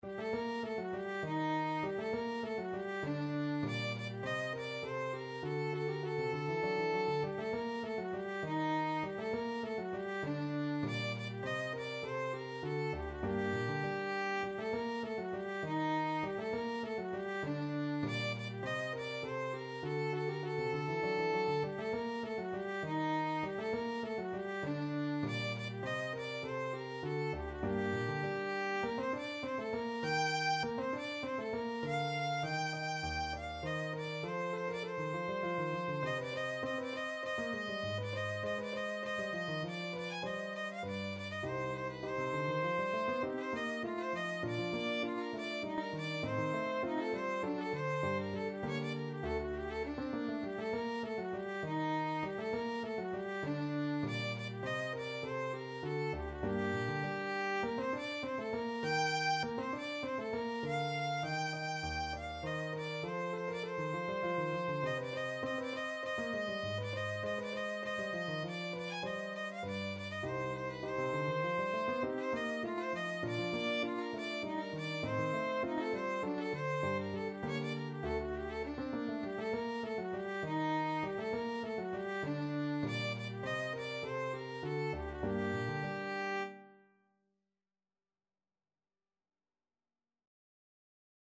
Violin version
3/4 (View more 3/4 Music)
Allegretto = 100
Classical (View more Classical Violin Music)